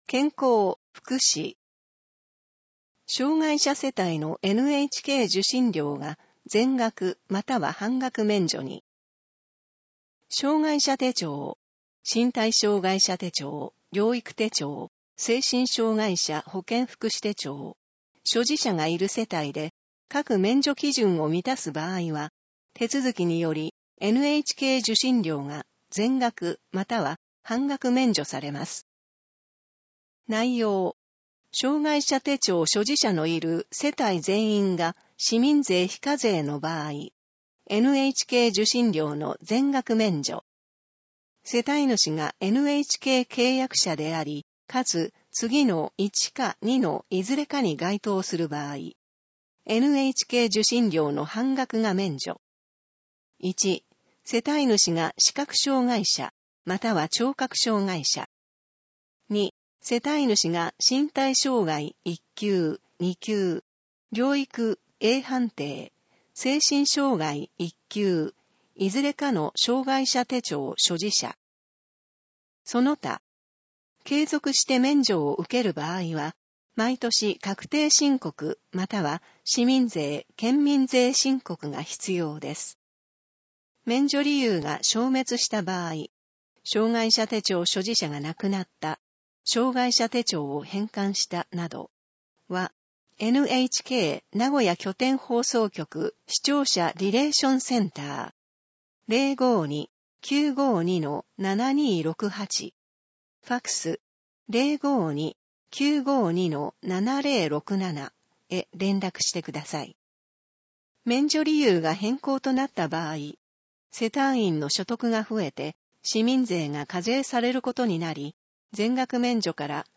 以上の音声データは、「音訳ボランティア安城ひびきの会」の協力で作成しています